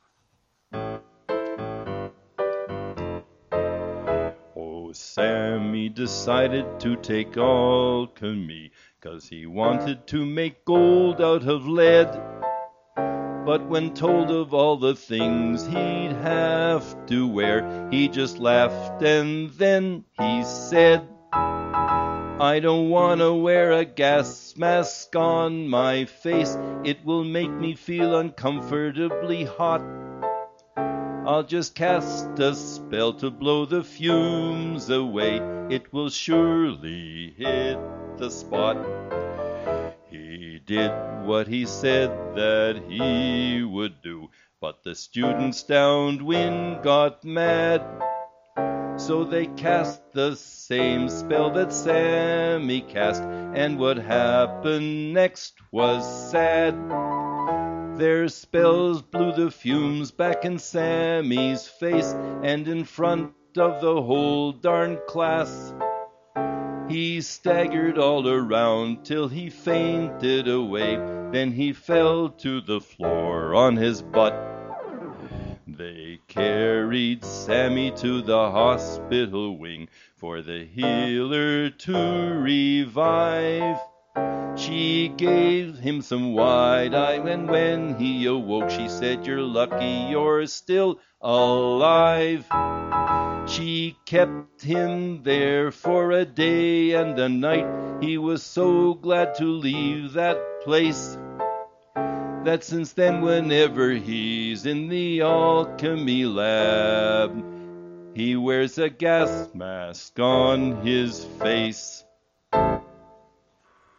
Songs sung by humans